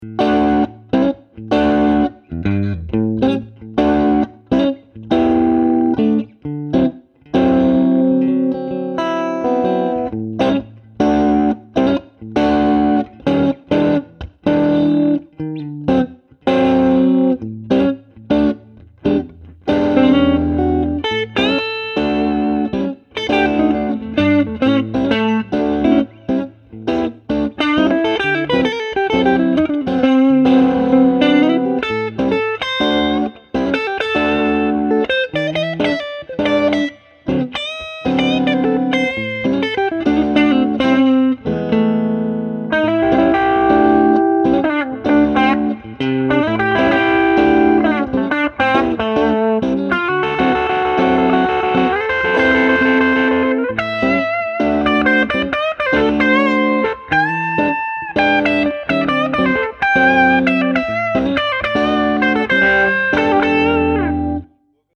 Mit seinen drei 10″-Speakern hat er mehr Druck und Volumen als die kleineren Modelle, behält aber den warmen, rauen Charakter, den man an diesen Amps so schätzt. Die Schaltung ist fast identisch mit der des 5F4 Super, klingt durch das größere Gehäuse aber etwas fülliger – mehr Raum, mehr Präsenz.